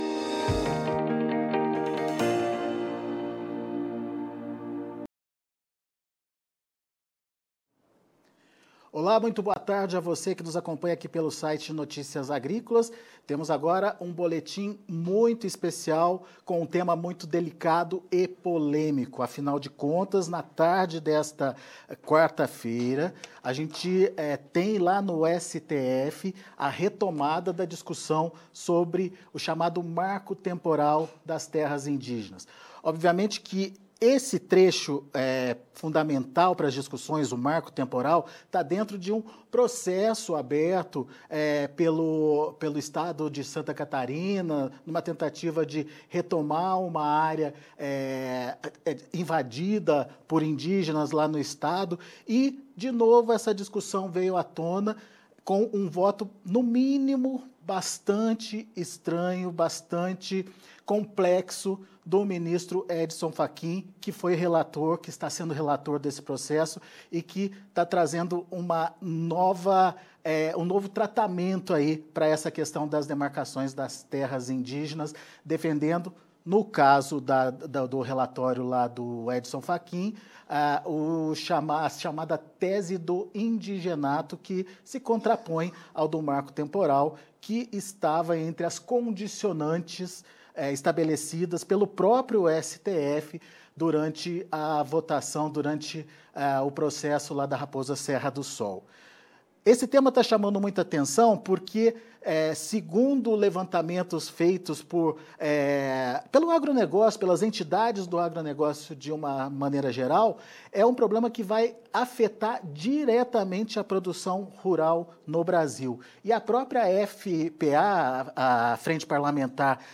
entrevista-neri-geller-noticias-agricolas.mp3